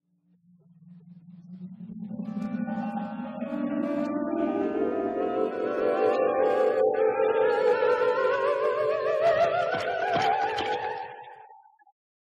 Гипнотический звук персонажа в мультфильме